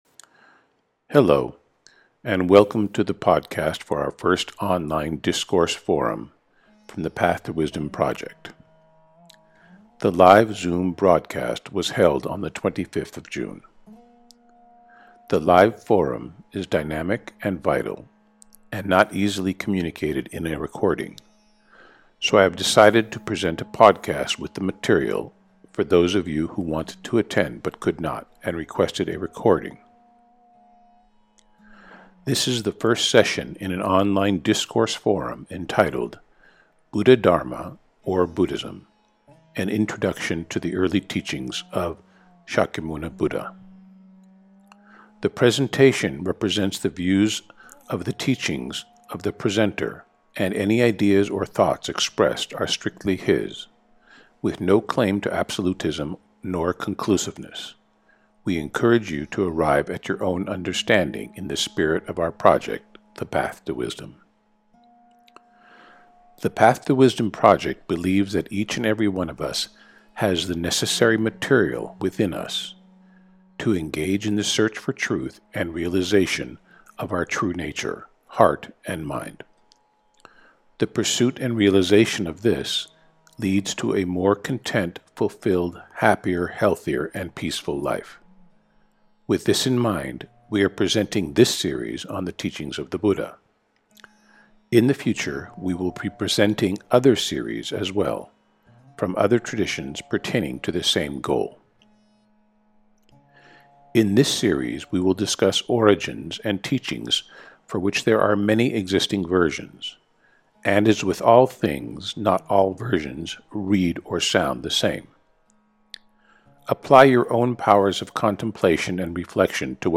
Podcast of our first Live Online Discourse/Forum Presented 25th June 2020. Buddhadharma or Buddhism - An initroduction to the early teachings of Shakyamuni Buddha’- Part 1 Handout material (pdf) Listen